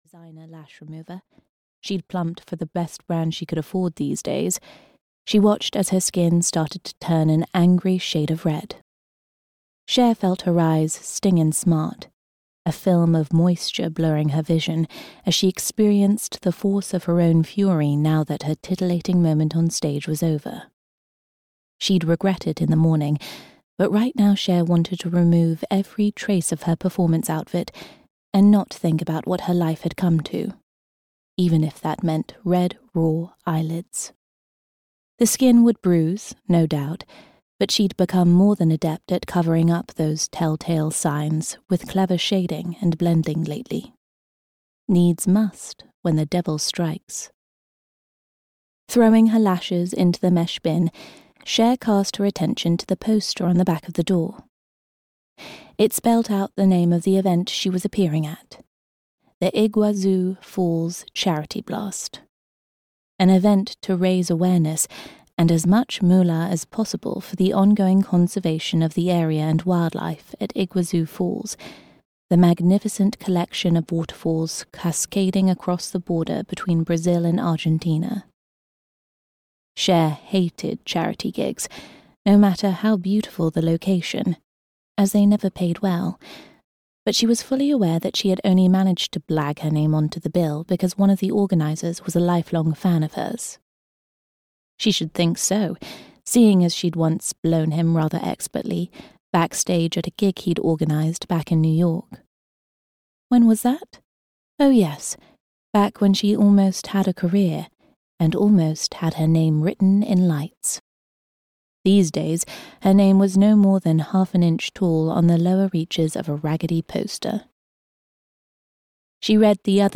Revenge (EN) audiokniha
Ukázka z knihy